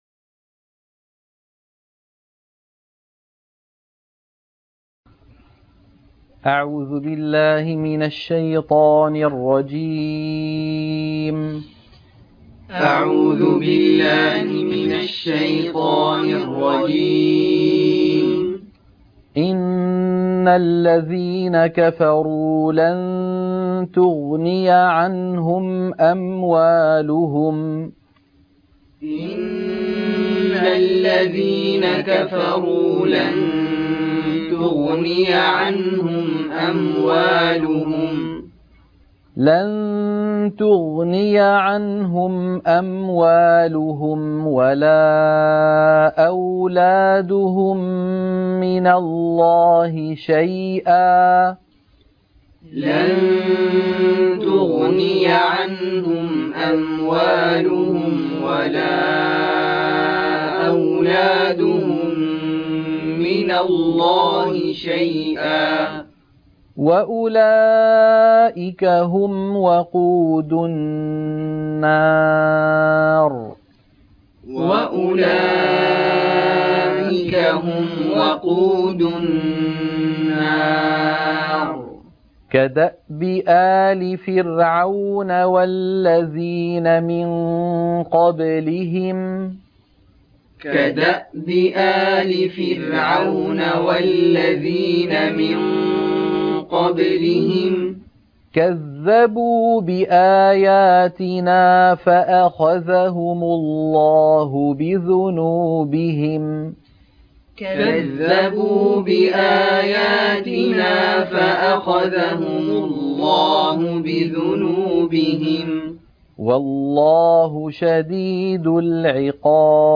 عنوان المادة تلقين سورة آل عمران - الصفحة 51 _ التلاوة المنهجية